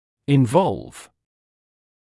[ɪn’vɔlv][ин’волв]включать в себя; касаться, затрагивать; вовлекать